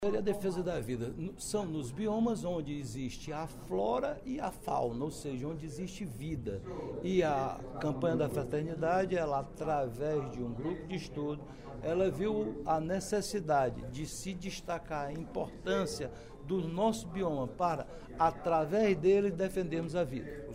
O pronunciamento foi feito durante o primeiro expediente da sessão plenária desta sexta-feira (03/03).